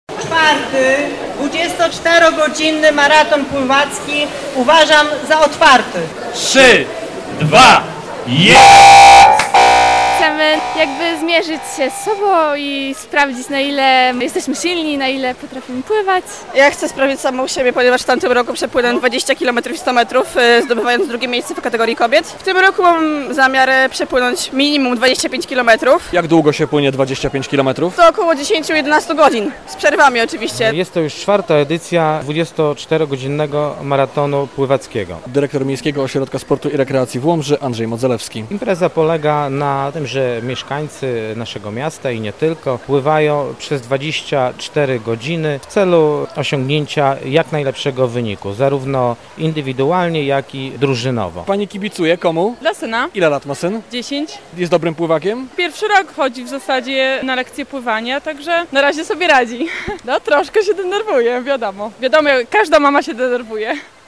Maraton pływacki w Łomży - relacja